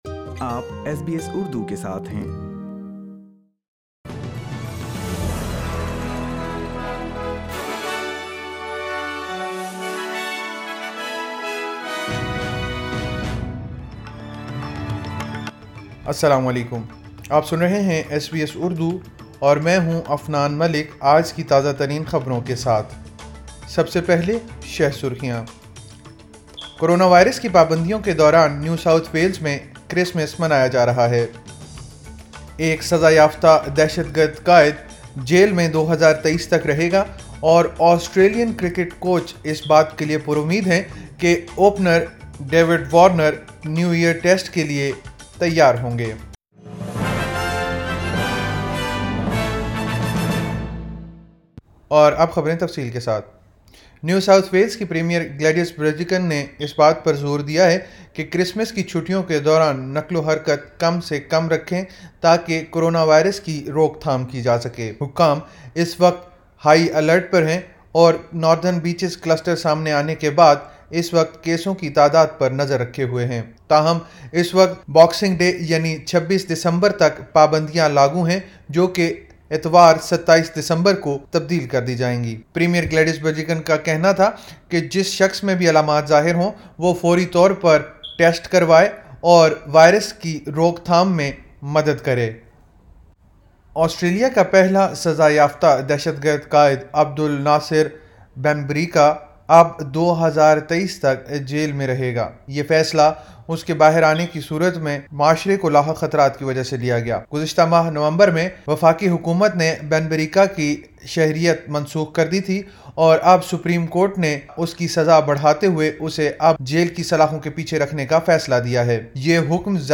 ایس بی ایس اردو خبریں 25 دسمبر 2020